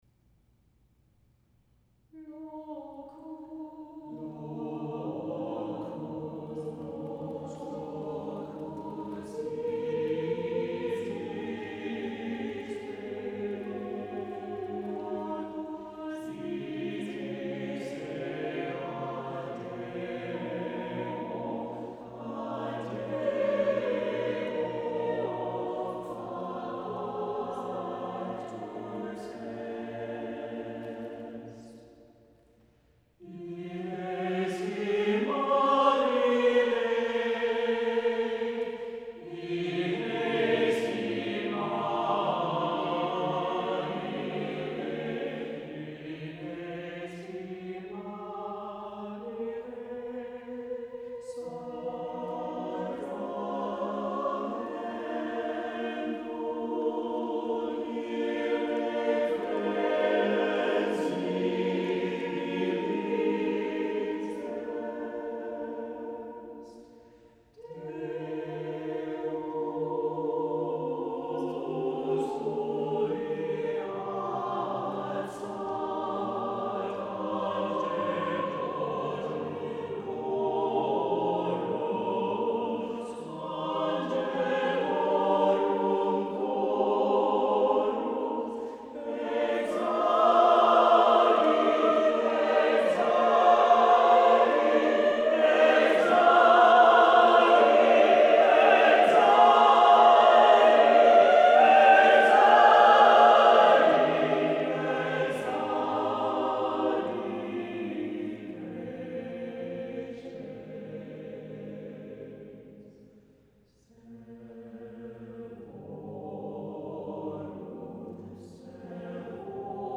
• key and modality give it a Renaissance atmosphere